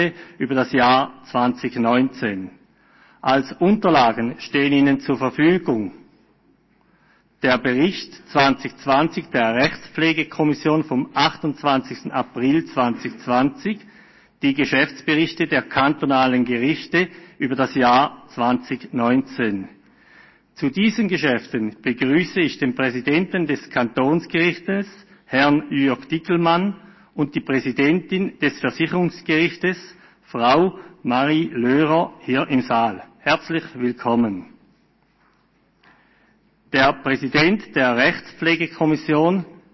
Präsident der Rechtspflegekommission: Die Rechtspflegekommission beantragt Kenntnisnahme von der Petition.